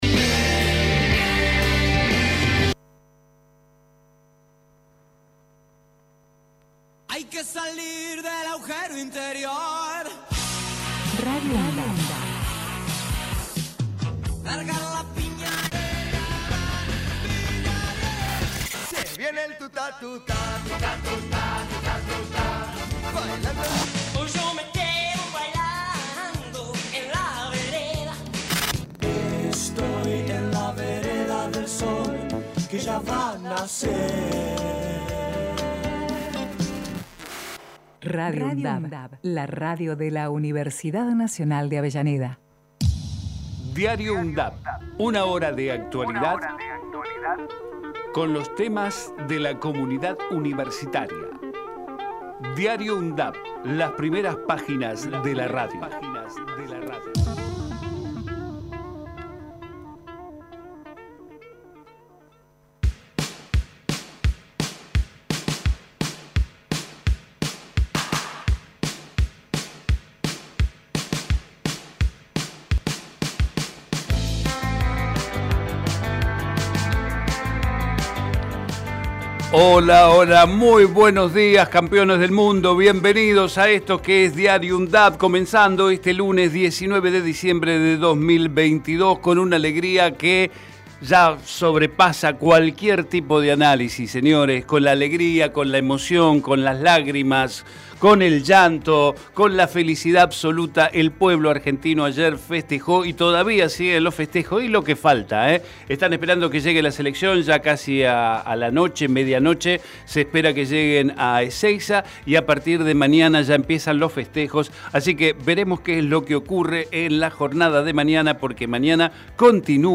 Diario UNDAV Texto de la nota: De lunes a viernes de 9 a 10 realizamos un repaso por la actualidad universitaria en las voces de los protagonistas, testimonios de quienes forman parte de la UNDAV. Investigamos la historia de las Universidades Nacionales de todo el país y compartimos entrevistas realizadas a referentes sociales, culturales y académicos.